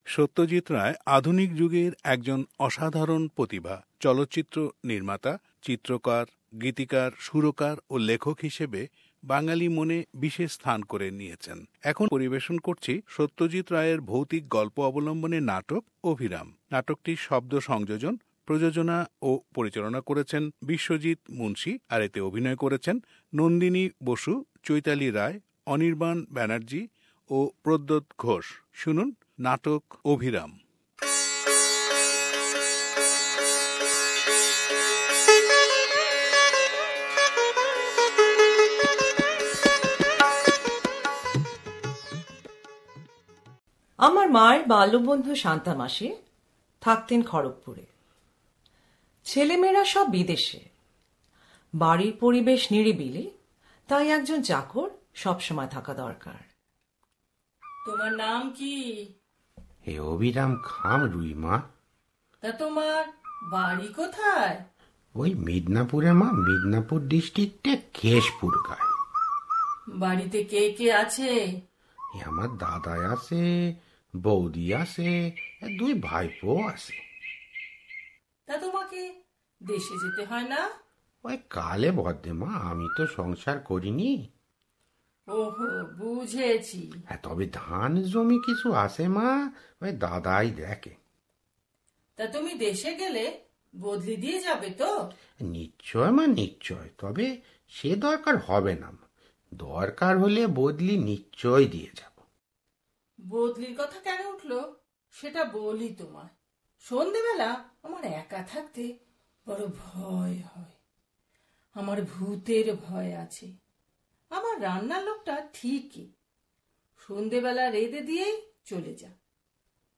Drama 'Abhiram' which captures the essence of Satyajit Ray's writing.